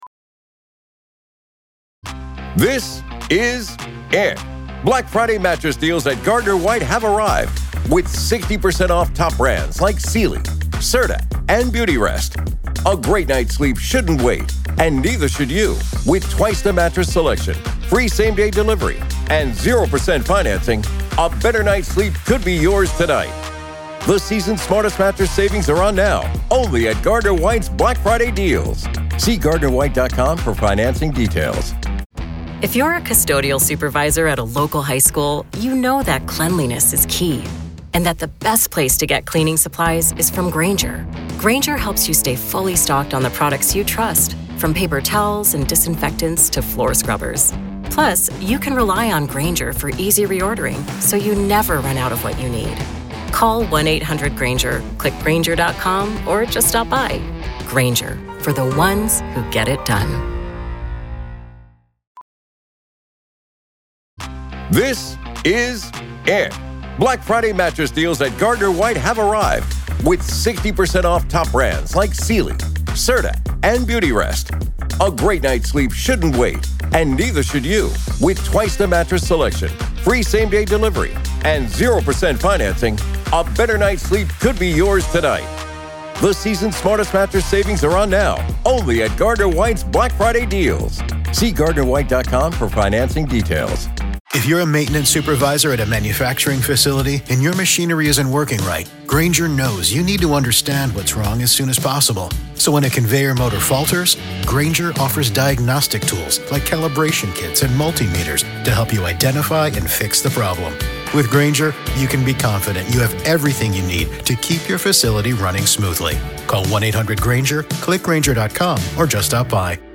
It’s a conversation about justice, redemption, and the moral courage it takes to stand in the face of real evil — and win.